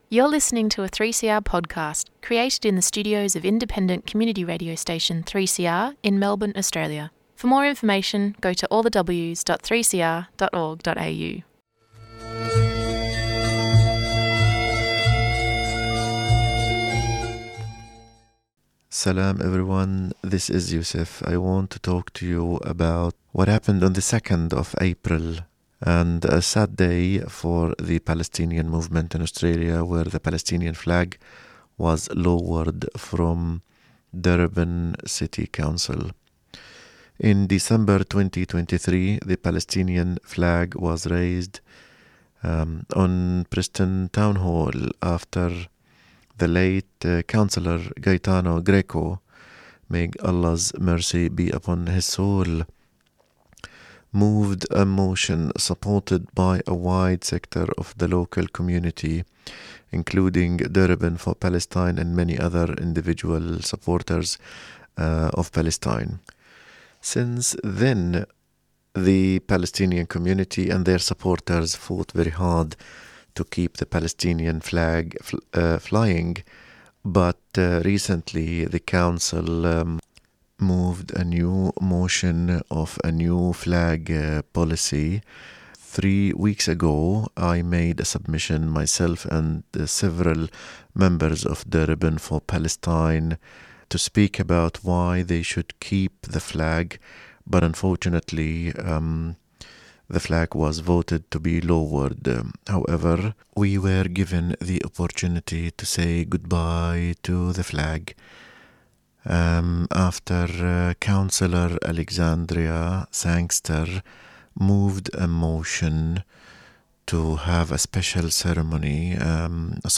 Ceremony for the lowering of the Palestinian flag at Preston Town Hall